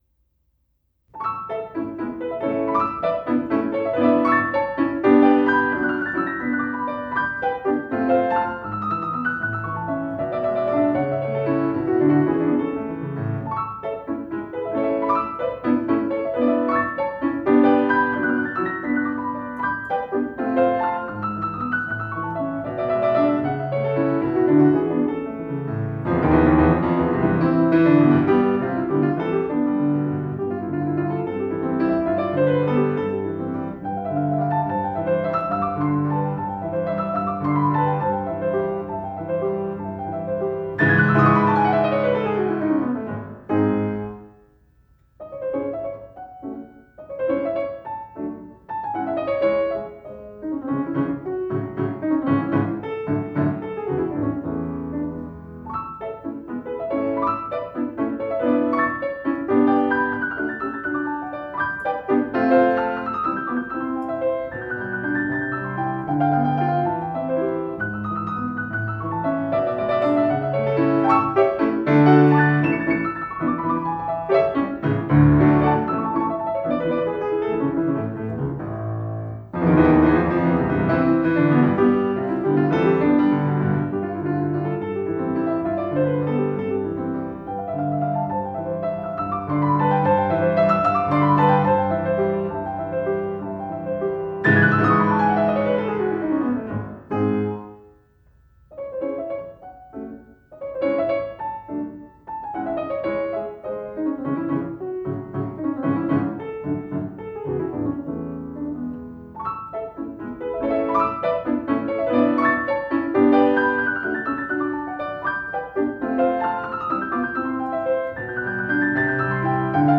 Postlude  F. Schubert, Sonata in A major, D 959
Scherzo (Allegro Vivace)
piano